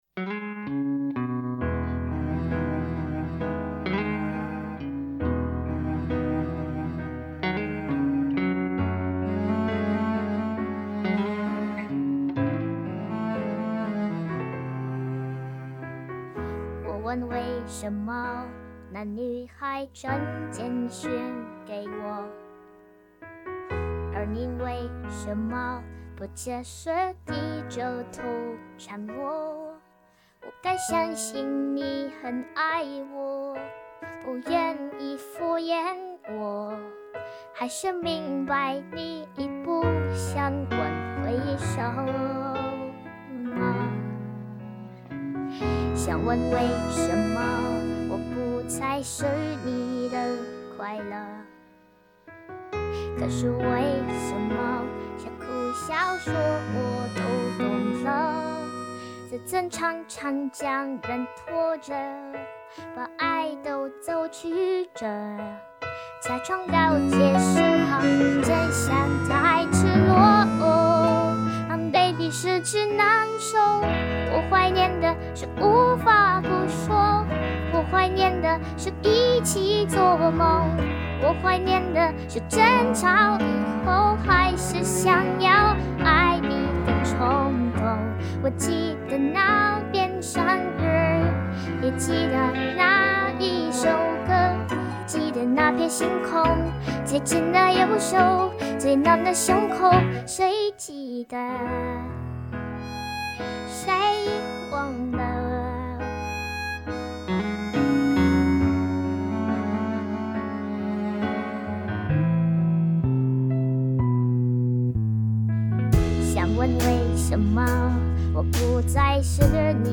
RVC声音模型| 懒羊羊声音模型